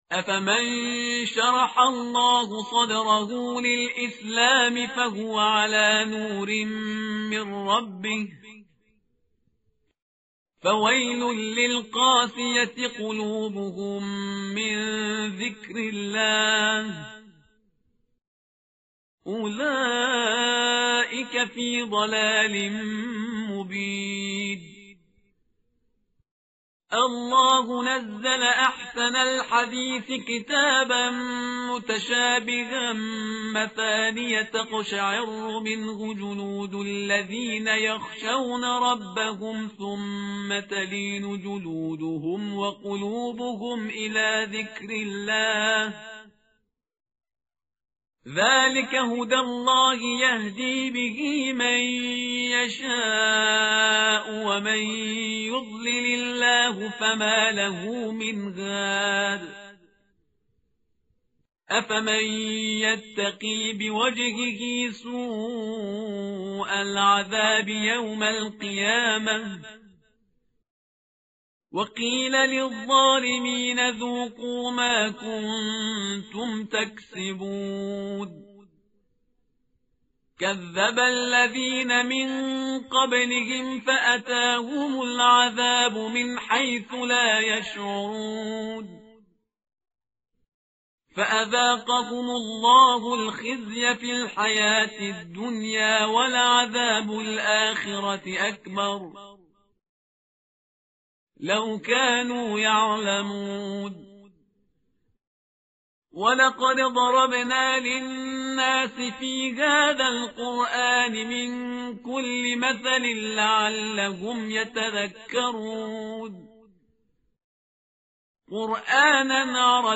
متن قرآن همراه باتلاوت قرآن و ترجمه
tartil_parhizgar_page_461.mp3